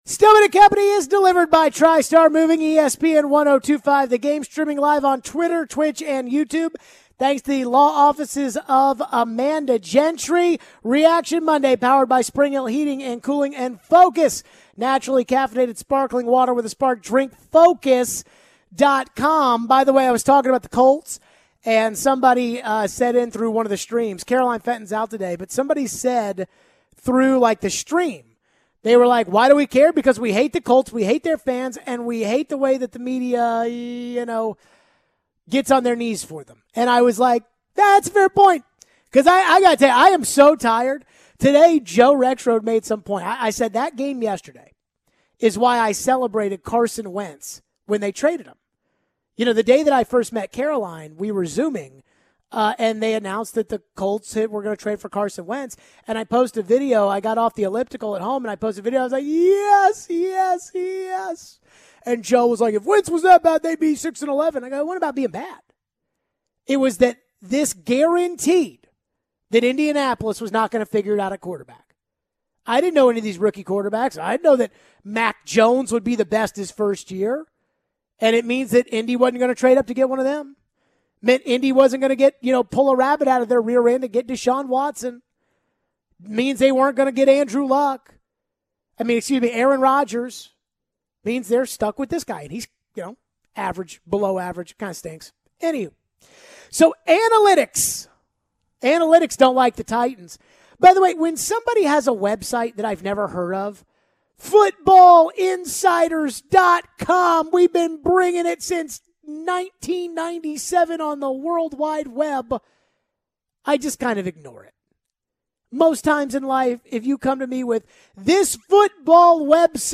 We go back to the phones. Plus, Antonio Brown walked off the field for the Bucs, do we think the NFL should help him?